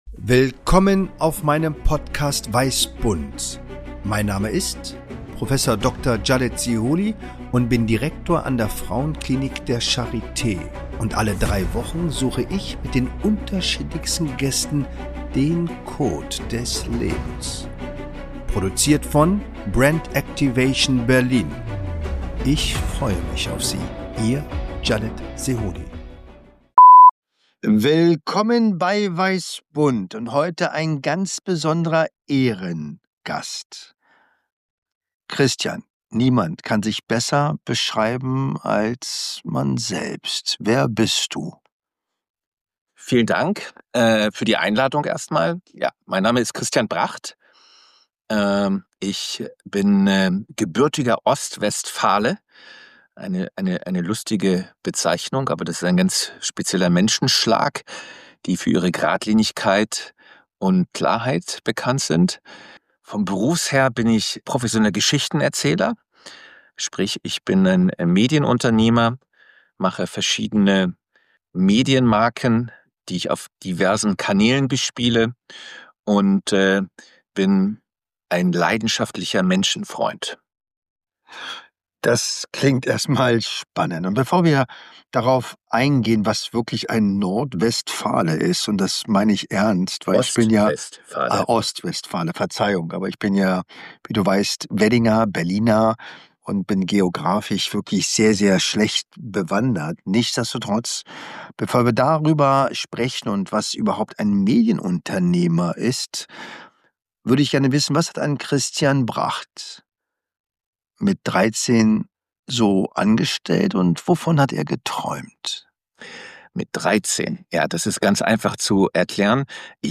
Spontan, intuitiv, ohne Skript, Improvisation pur!